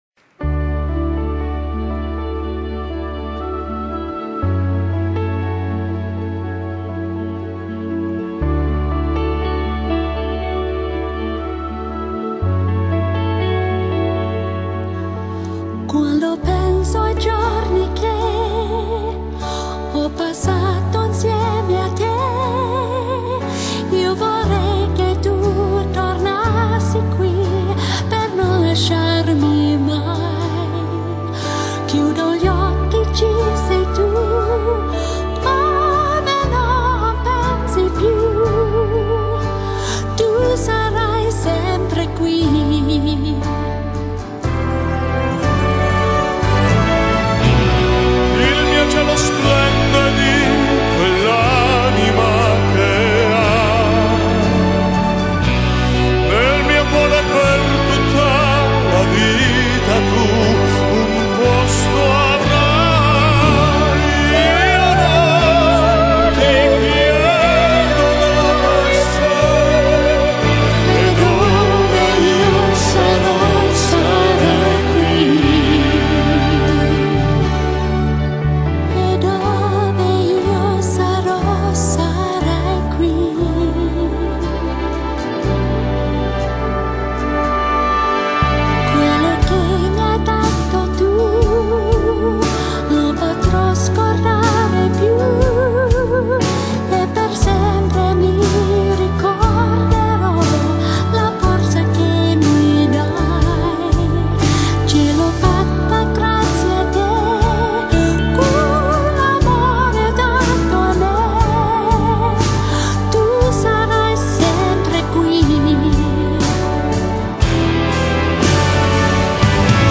維也納世界遺產 聖史蒂芬大教堂現場紀實